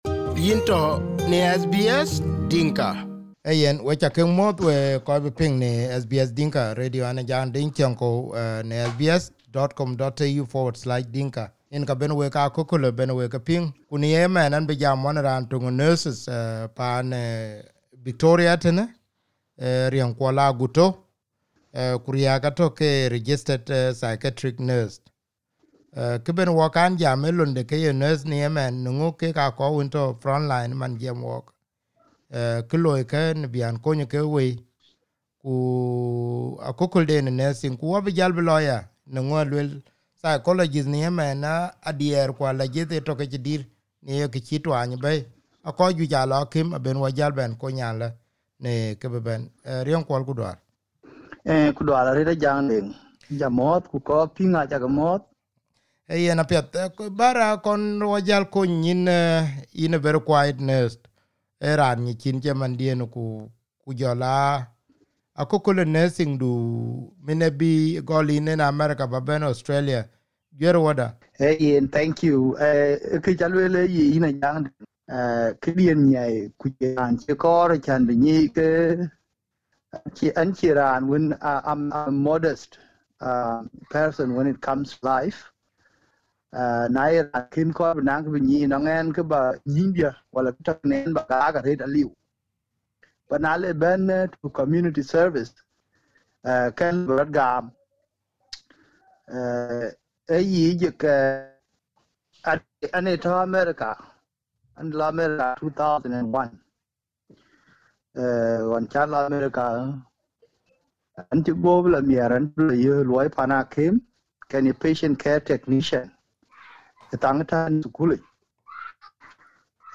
This is part one of the interview, and part two will soon be published.